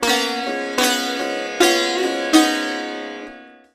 SITAR GRV 14.wav